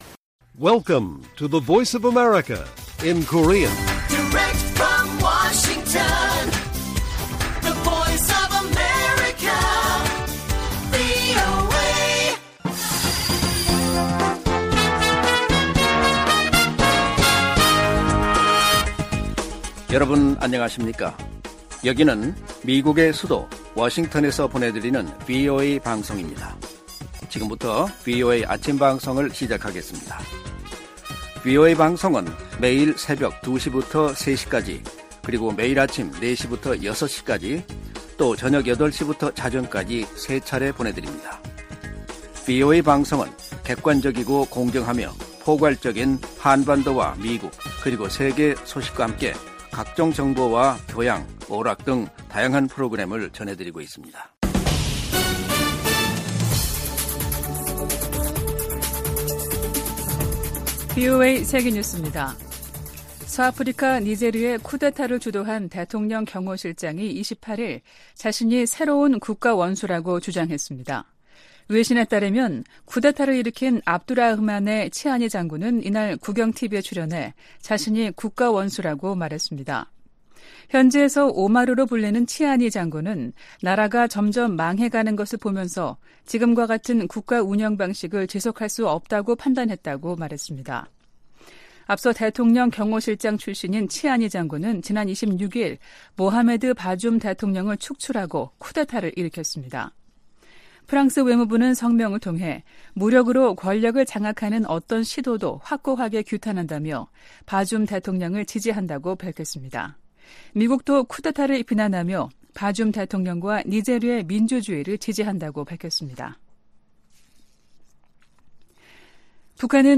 세계 뉴스와 함께 미국의 모든 것을 소개하는 '생방송 여기는 워싱턴입니다', 2023년 7월 29일 아침 방송입니다. '지구촌 오늘'에서는 우크라이나군이 남동부 전선을 중심으로 새로운 공세를 시작했다고 보도된 소식 전해드리고, '아메리카 나우'에서는 기준금리 0.25%P 인상 이야기 살펴보겠습니다.